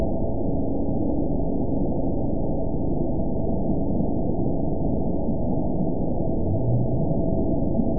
event 922870 date 04/29/25 time 15:28:14 GMT (1 month, 2 weeks ago) score 8.81 location TSS-AB02 detected by nrw target species NRW annotations +NRW Spectrogram: Frequency (kHz) vs. Time (s) audio not available .wav